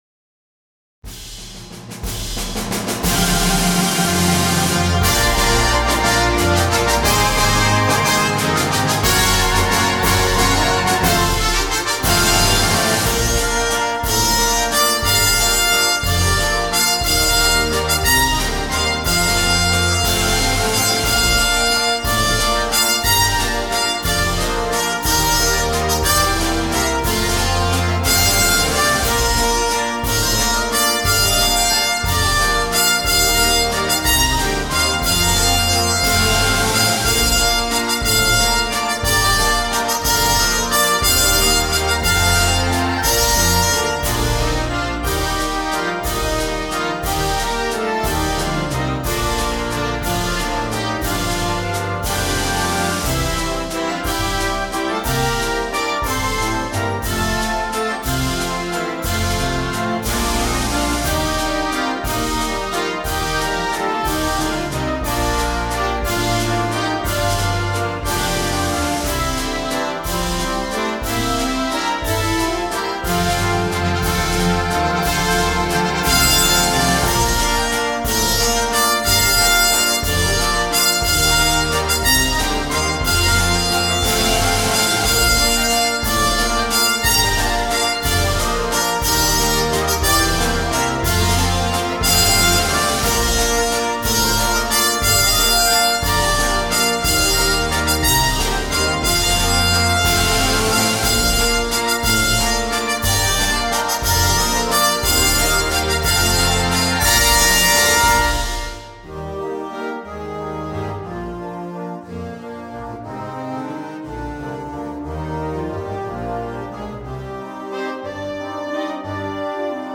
in 3/4 (Waltz) time
Timpani
Glockenspiel